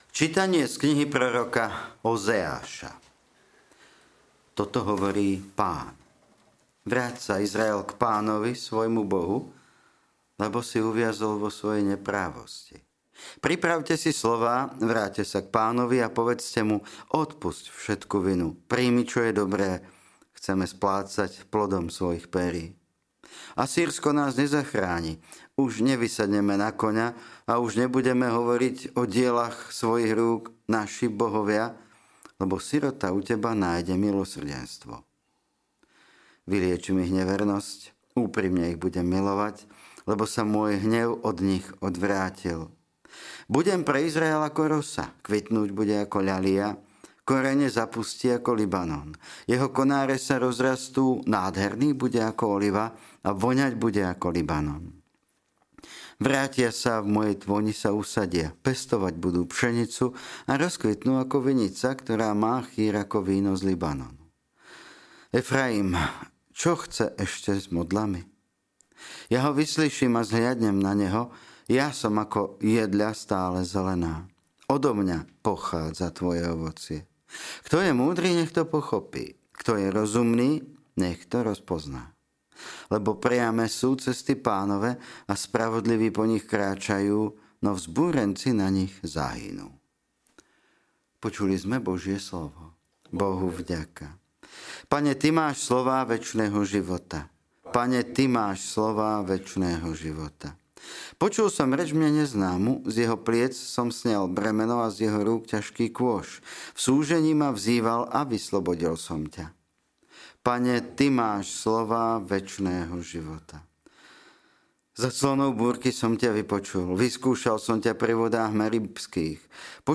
LITURGICKÉ ČÍTANIA | 28. marca 2025